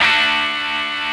Remix10ChordD.wav